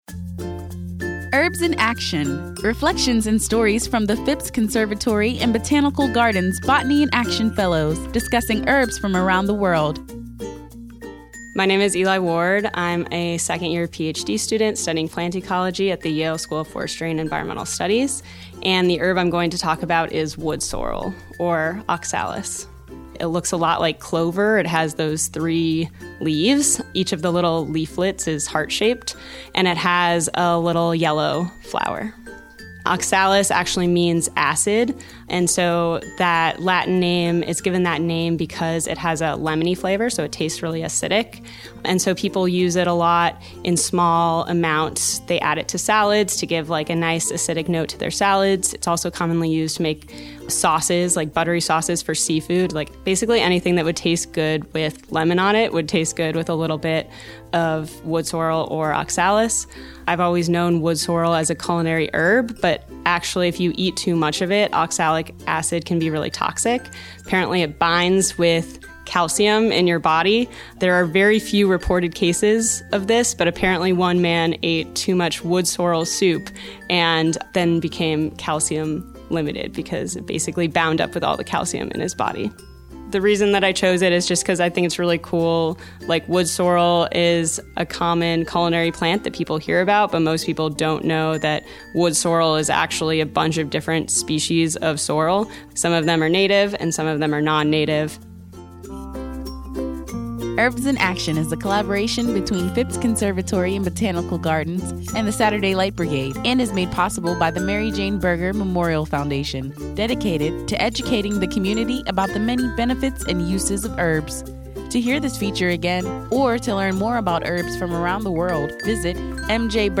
Listen as they share their knowledge of and experiences with these herbs as botanists of the world.